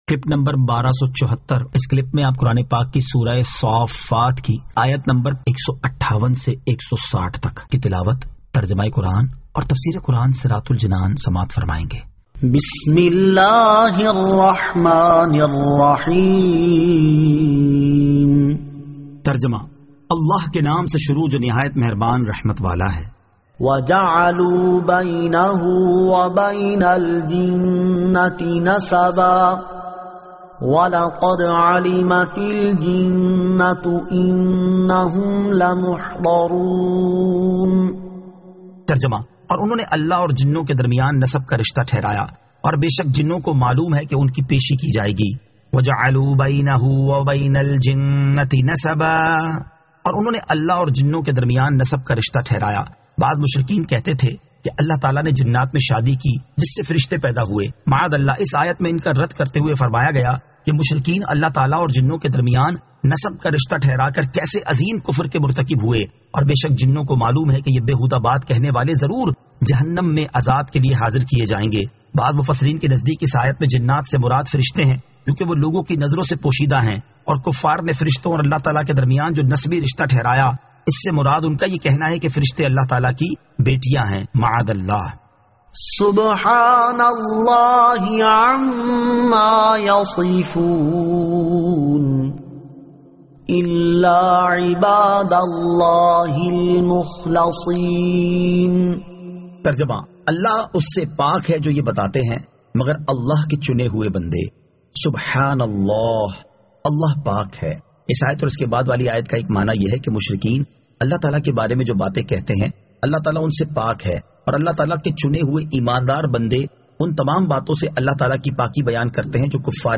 Surah As-Saaffat 158 To 160 Tilawat , Tarjama , Tafseer